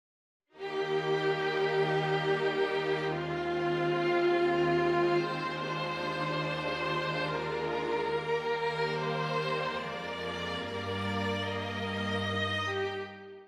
Fac-simile manuscript of beginning of Adagio in Max Bruch’s first Violin Concerto.